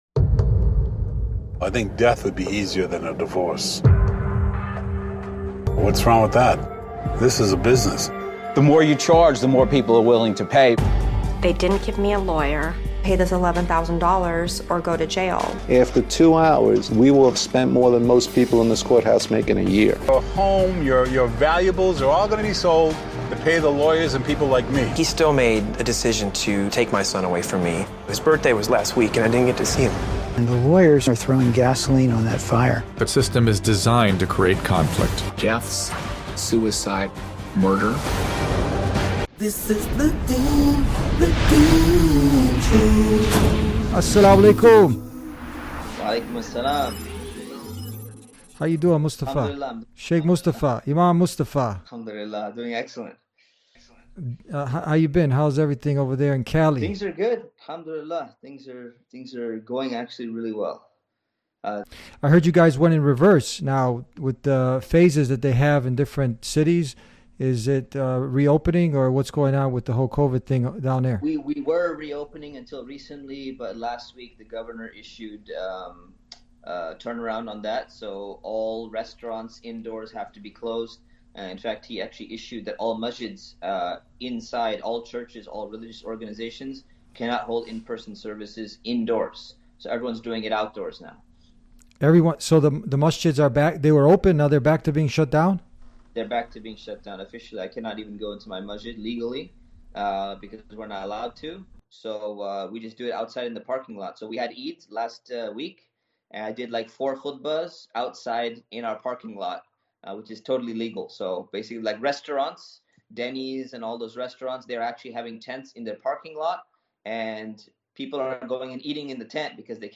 This 2014 film, reviewed on The Deen Show alongside a knowledgeable guest with direct experience in Islamic family counseling, does more than expose the corruption of American family courts.